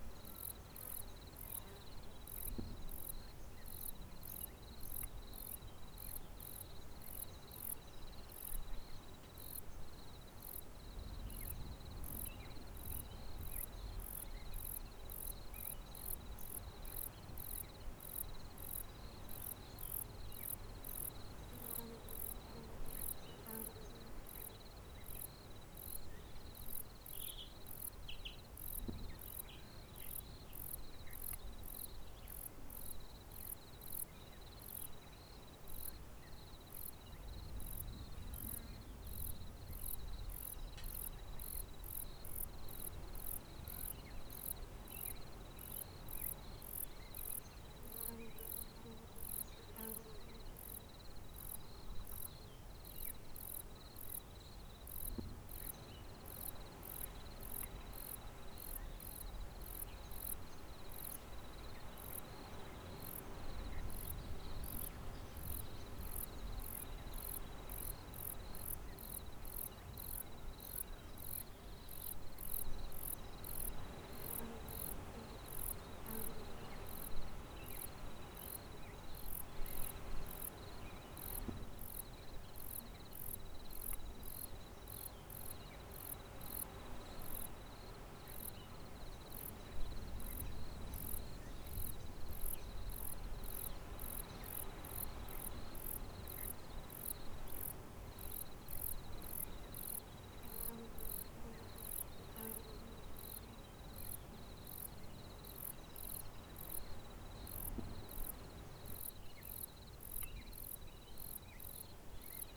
houses_evening.ogg